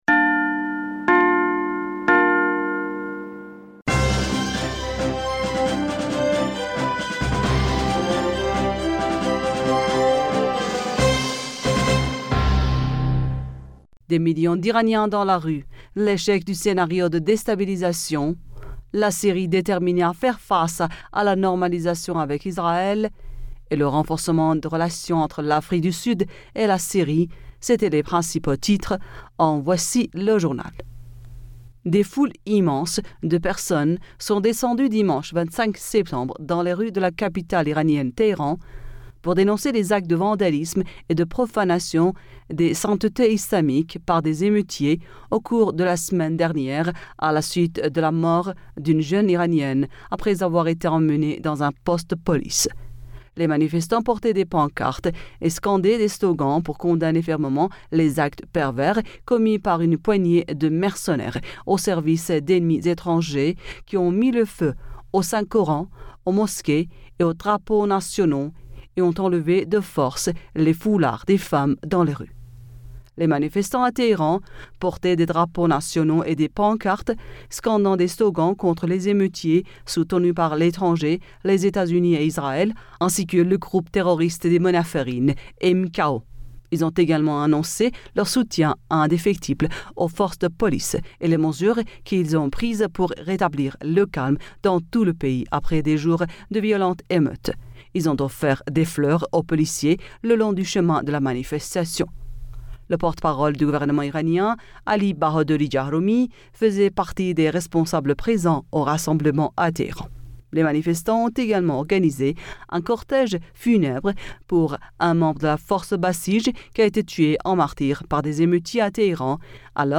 Bulletin d'information Du 26 Septembre